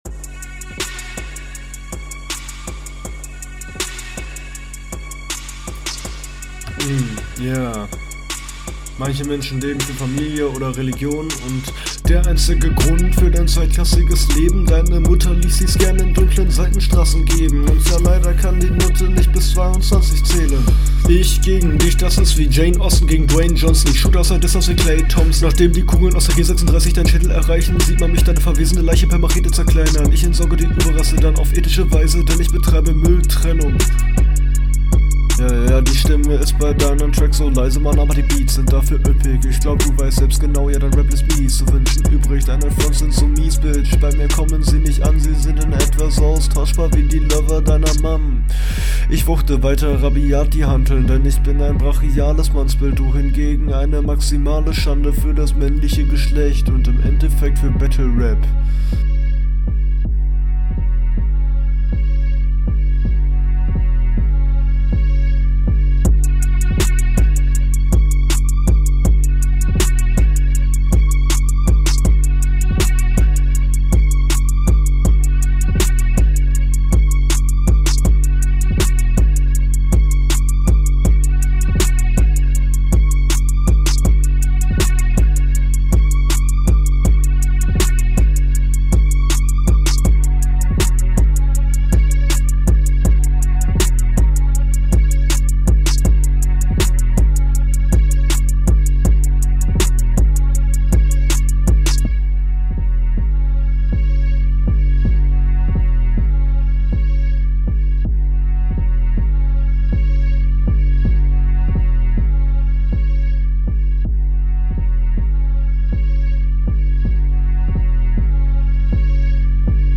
digga cutte doch den beat weg. Dachte erstt 5 min runde bruh